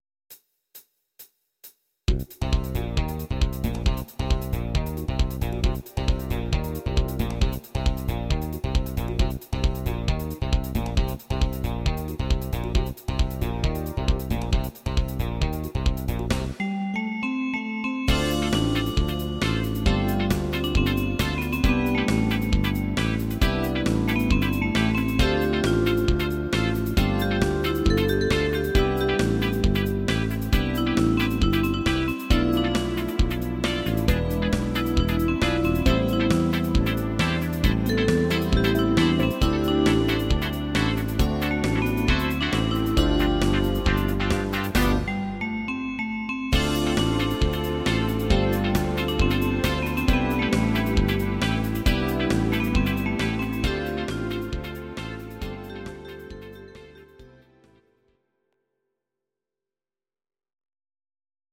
These are MP3 versions of our MIDI file catalogue.
Please note: no vocals and no karaoke included.
Your-Mix: Disco (726)